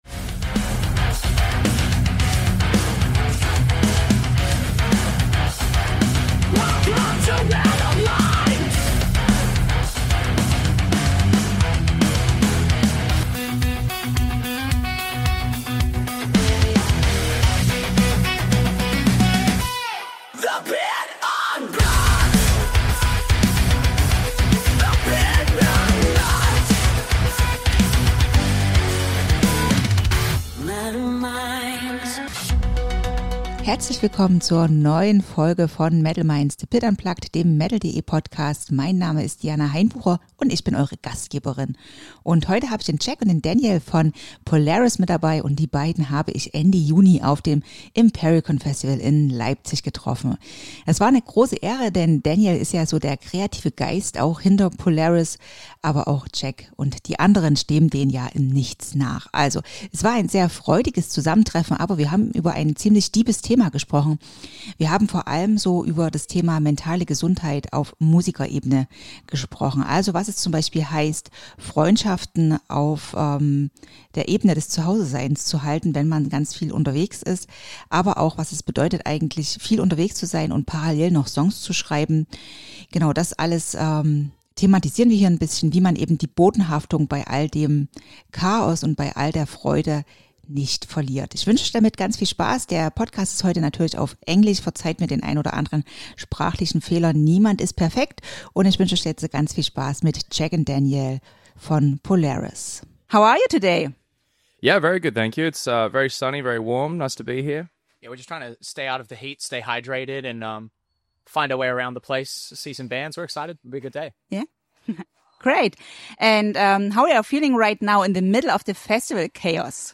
Ein ehrliches Gespräch über die Höhen und Tiefen des Musikerlebens!